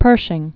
(pûrshĭng, -zhĭng), John Joseph Known as "Black Jack." 1860-1948.